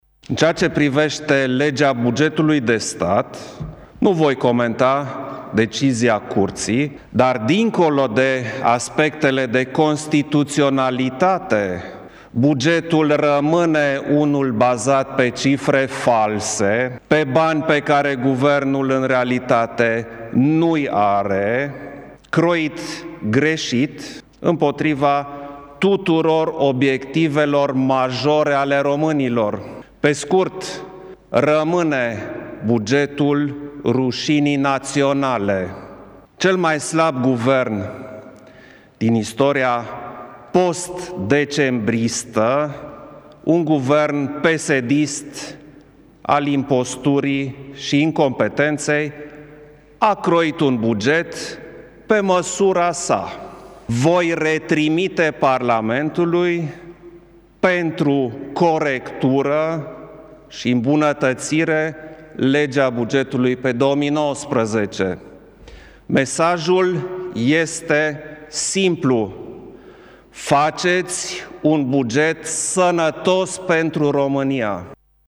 Klaus Iohannis a declarat în urmă cu puțin timp că bugetul pe 2019 rămâne ”bugetul rușinii naționale”, subliniind că Executivul este cel mai slab din istoria postdecembristă. Președintele a precizat că Proiectul de Buget pe anul 2019 este construit pe scenarii fanteziste: